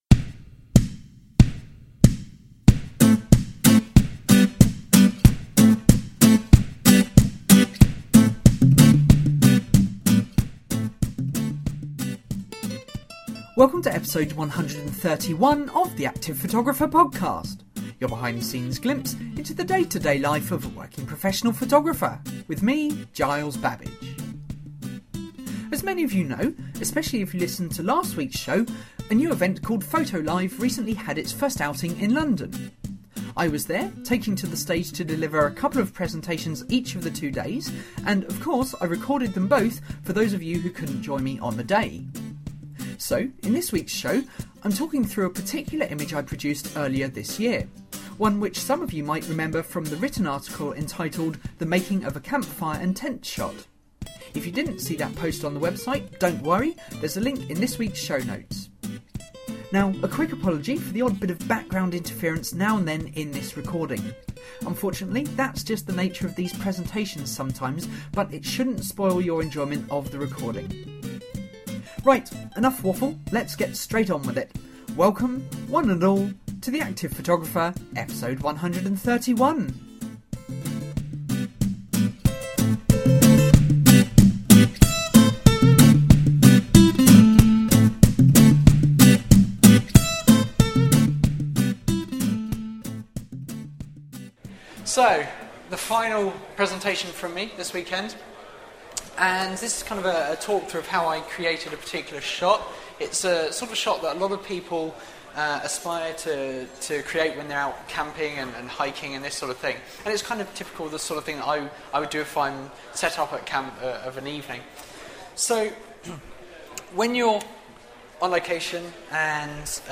This week: I’m bringing you my second talk recorded at Photo Live 2013.